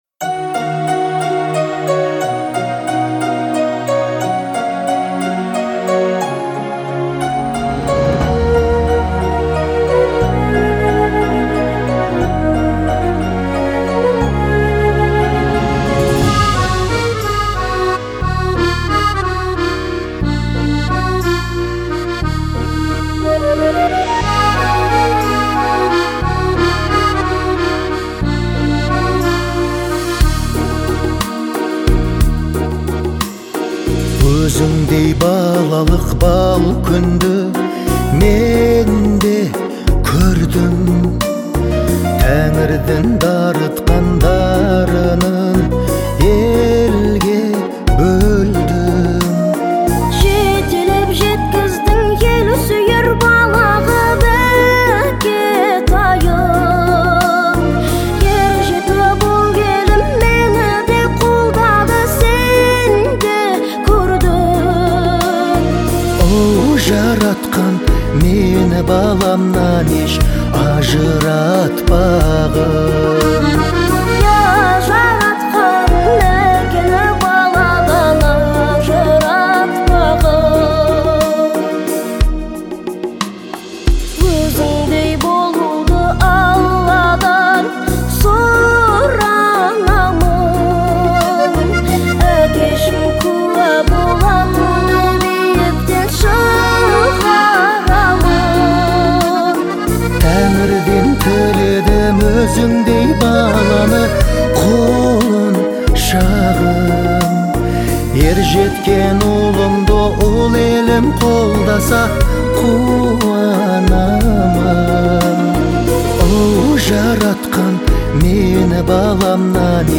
это трогательная музыкальная композиция в жанре поп